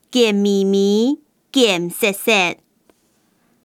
Hakka_tts